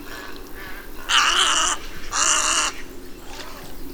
Snowy Egret
Egretta thula
VOZ: Un graznido grave.